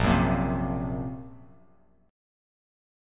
Bbsus2#5 chord